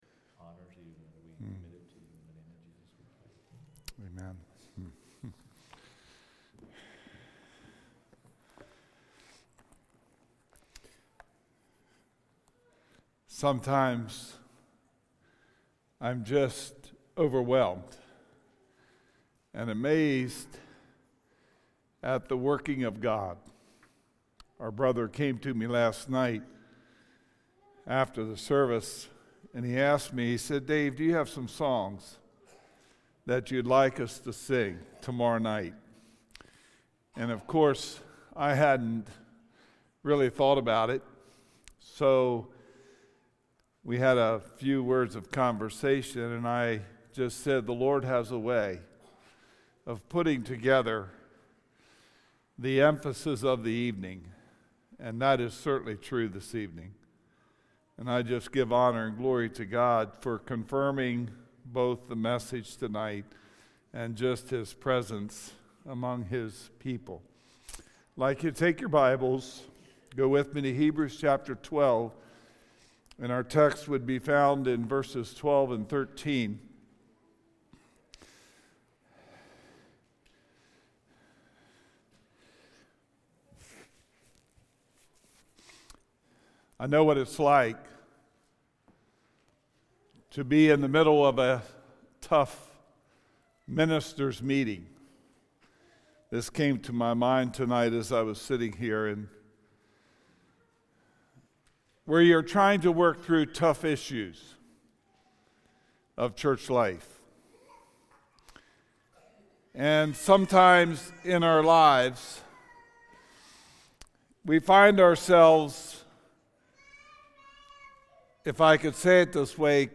Sermons | Christian Life Mennonite
REVIVAL MEETINGS DAY 3